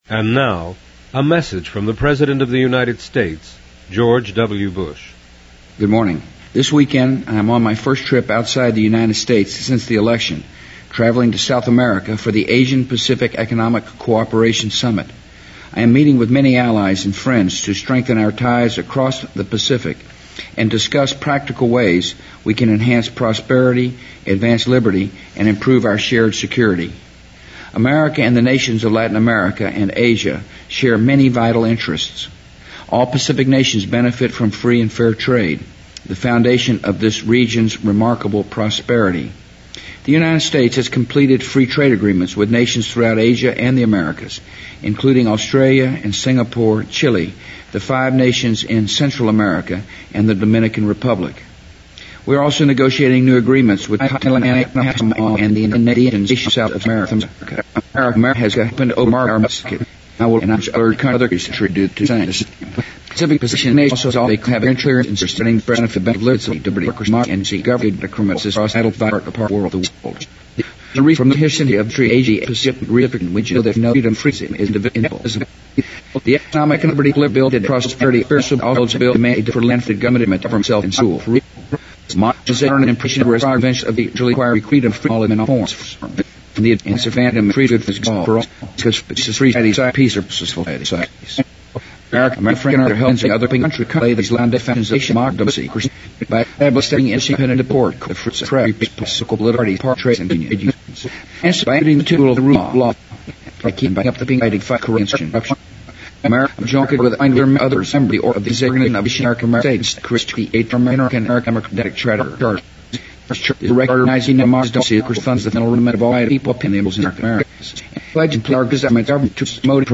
President Bush-2004-11-20电台演说 听力文件下载—在线英语听力室